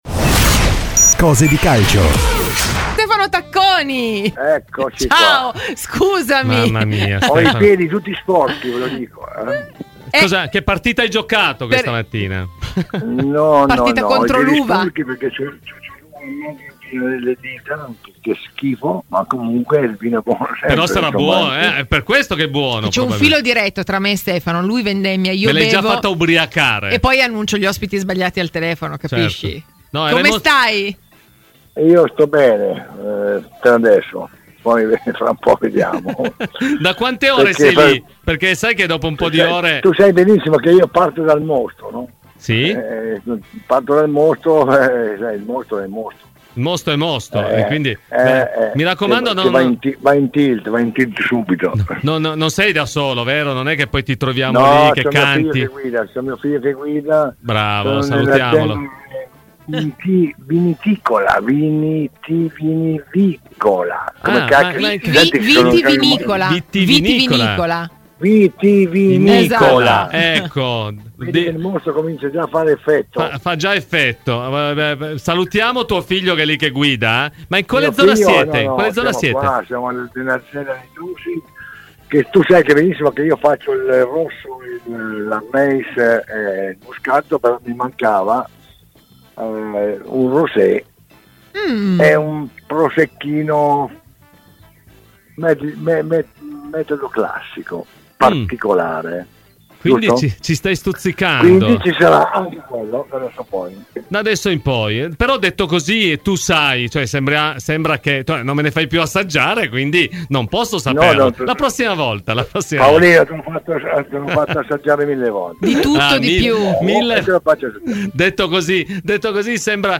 Ai microfoni di Radio Bianconera, nel corso di ‘Cose di Calcio’, è intervenuto l’ex Juventus Stefano Tacconi: “Juventus in ritardo?